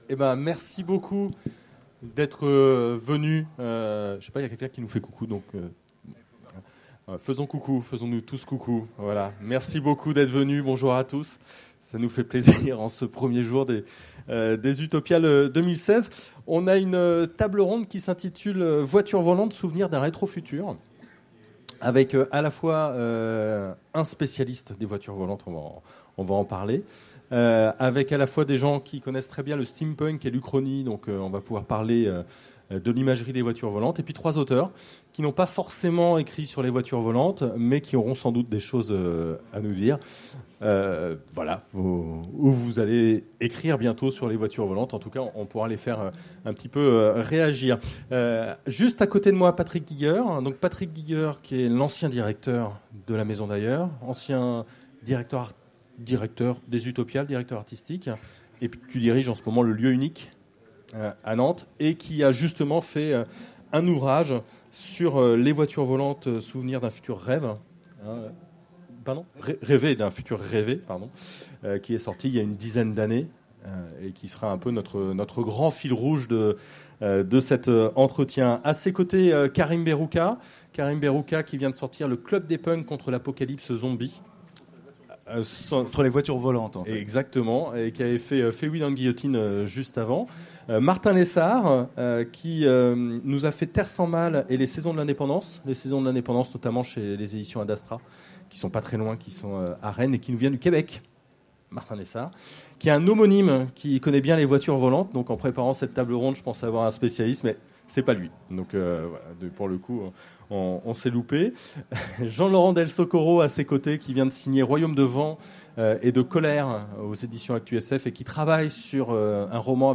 Utopiales 2016 : Conférence Voitures volantes souvenirs d’un rétro-futur